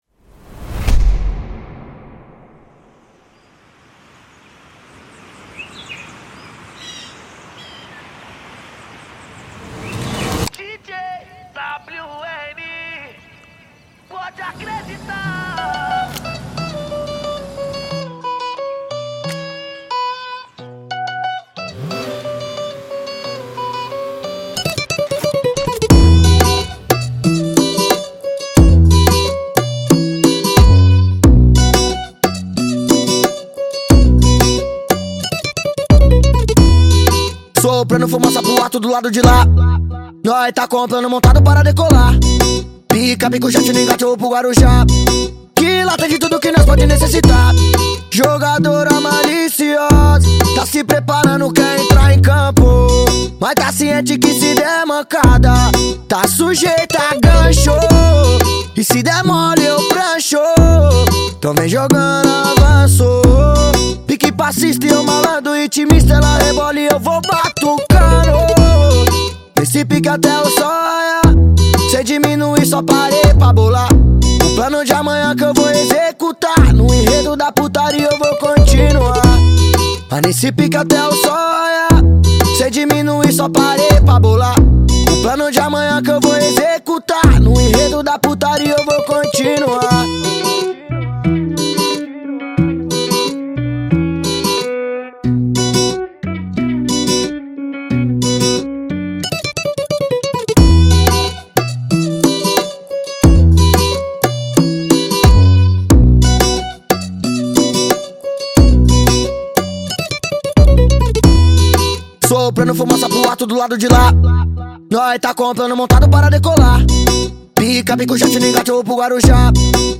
2024-03-18 23:30:33 Gênero: Funk Views